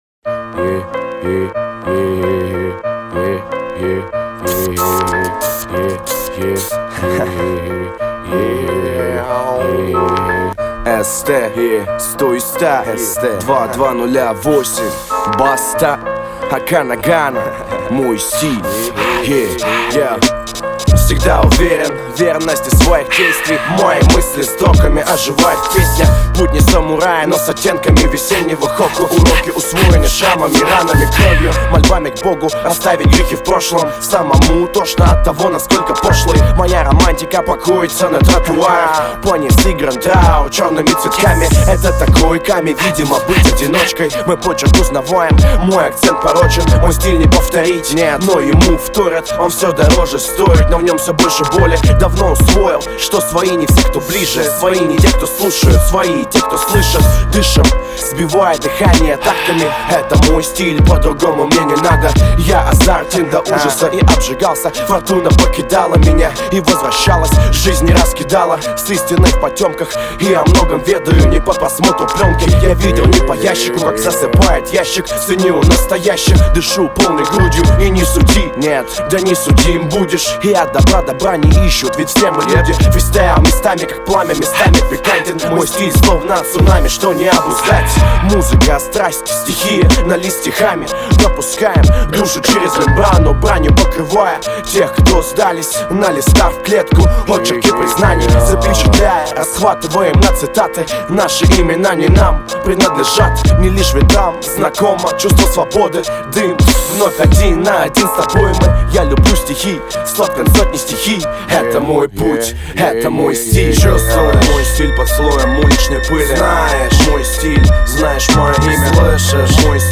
Список файлов рубрики (рэп)...